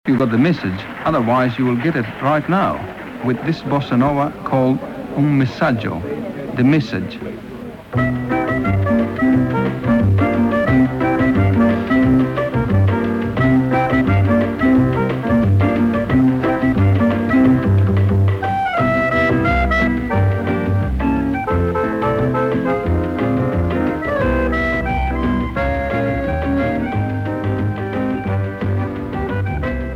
accordion
Bossa nova